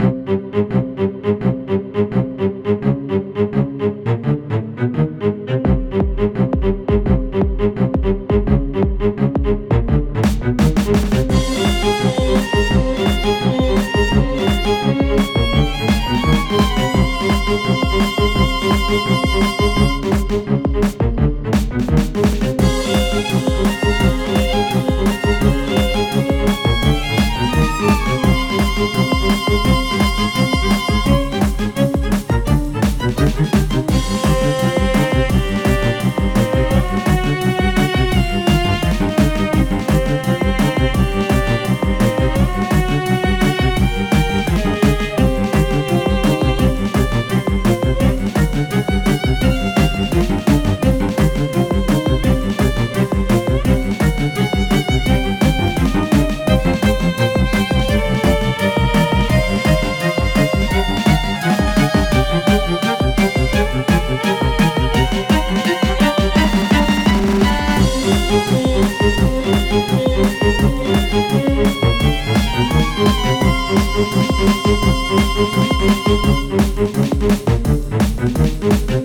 💬過去に深い悲しみを背負ったキャラを表現した弦楽四重奏風の戦闘BGMです。
OGG音量調整版